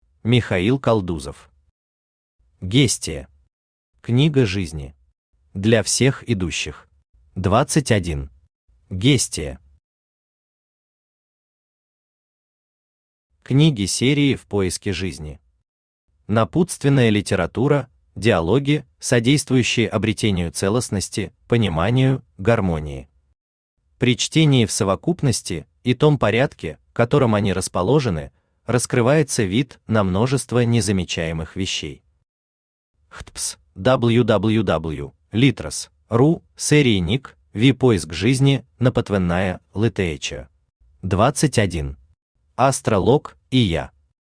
Аудиокнига Гестия. Книга жизни. Для всех идущих | Библиотека аудиокниг
Для всех идущих Автор Михаил Константинович Калдузов Читает аудиокнигу Авточтец ЛитРес.